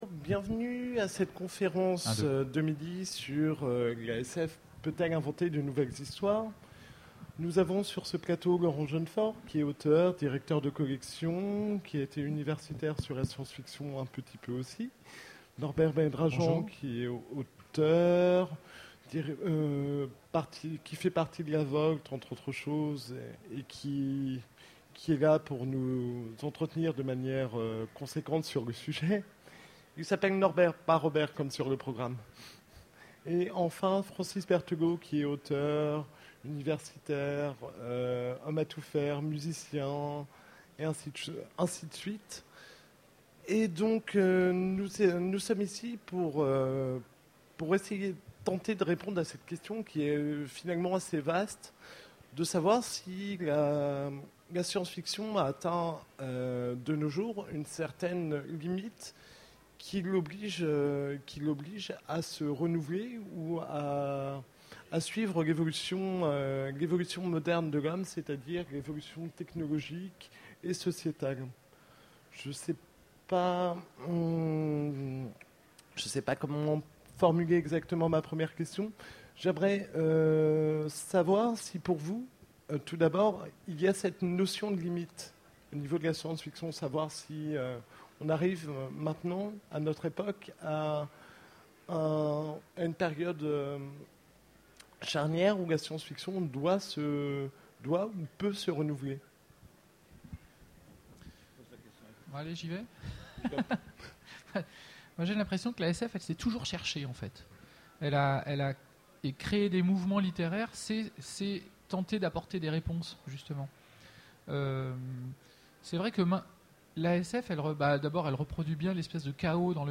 Utopiales 2011 : Conférence La Science Fiction peut-elle inventer de nouvelles histoires ?